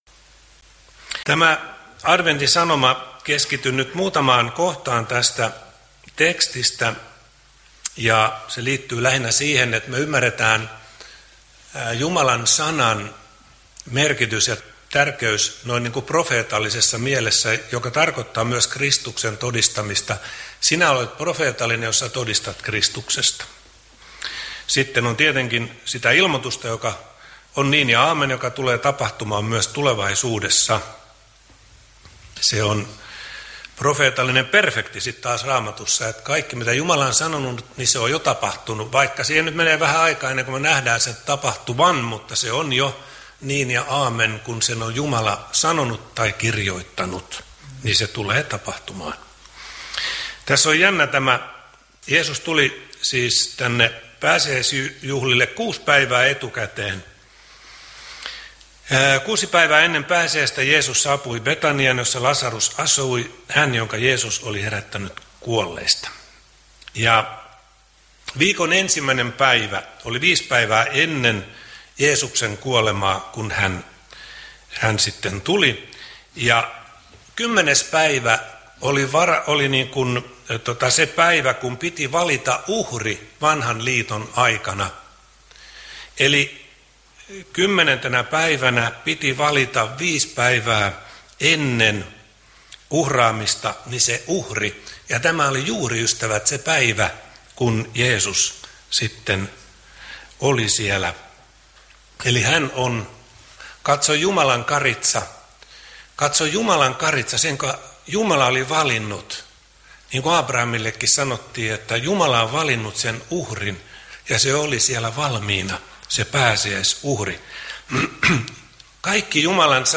saarna Niinisalon kirkossa 1. adventtisunnuntaina Tekstinä Matt. 21:1–9